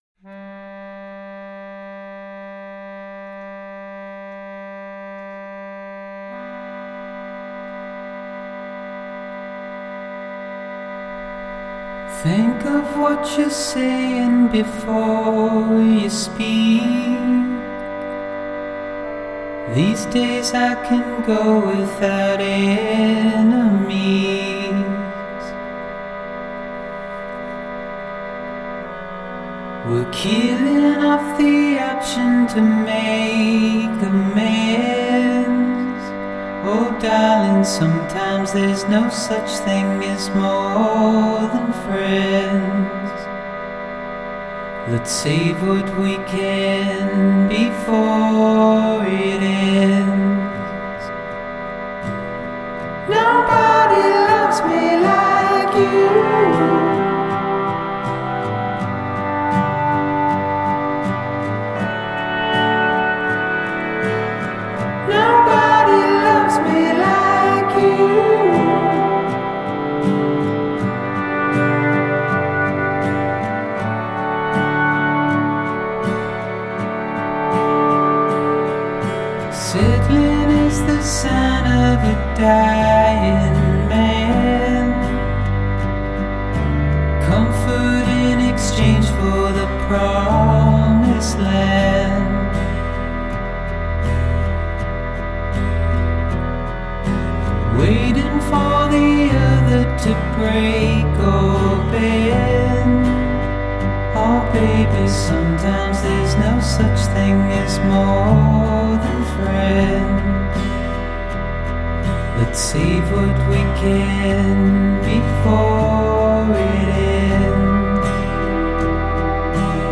بیش از حد صداش قشنگه ( بود )